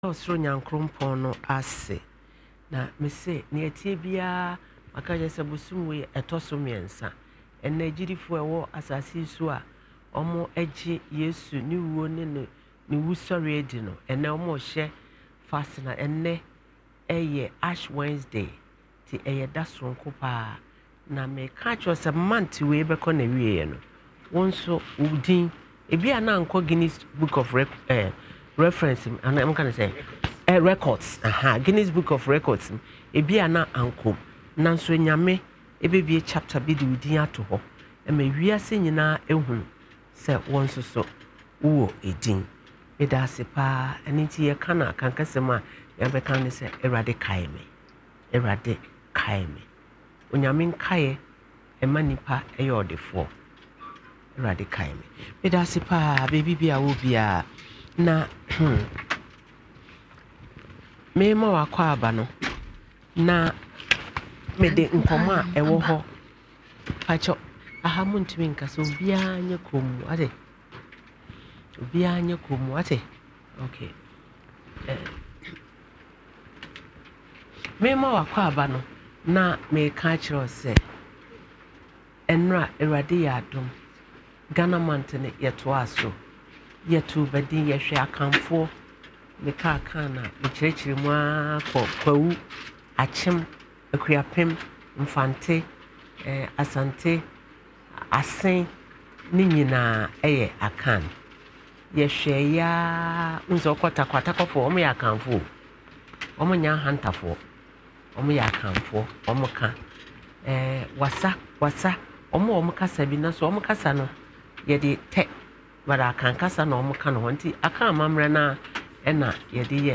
Panel discussion of social and moral issues affecting the lives of the general population.